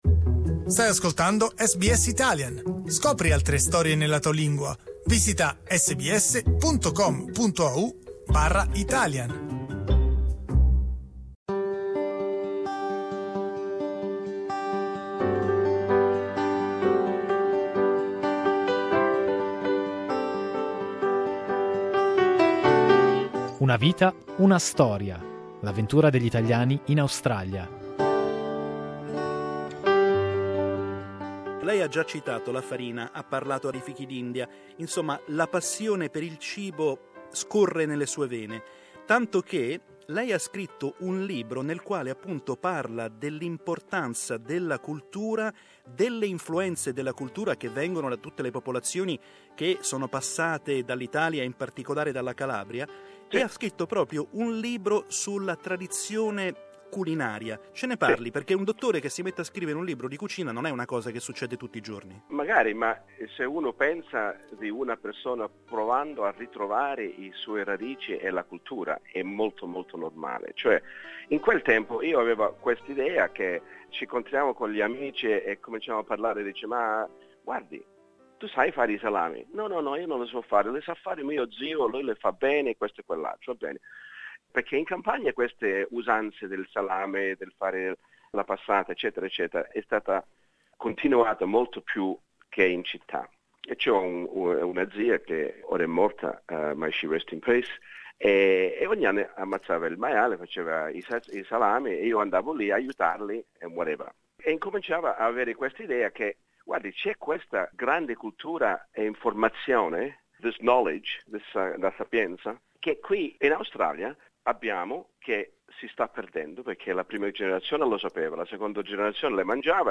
Last week, in the first part of our conversation, we talked about his youth in Australia and his first trips to Italy. Today we delve into his career as a doctor and his passion for traditional Italian cooking.